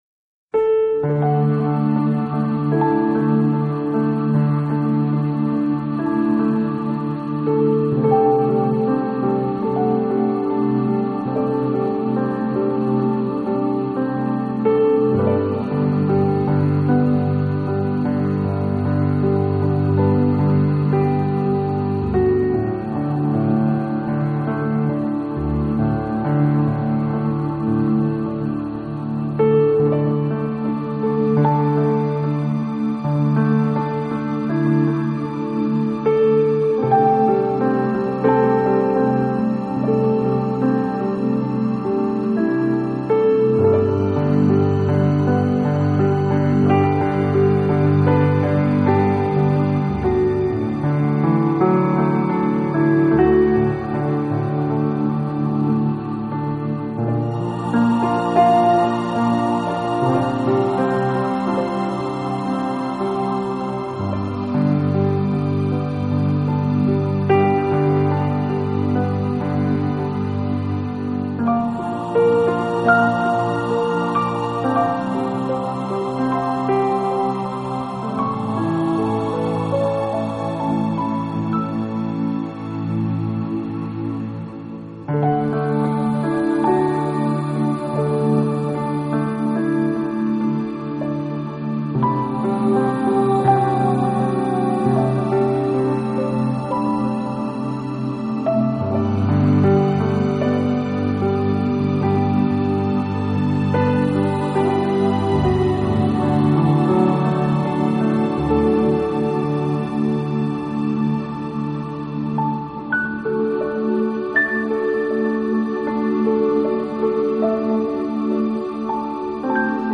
类型  New Age
的弦乐，合唱，合成乐，竖琴以及魔术般的编钟。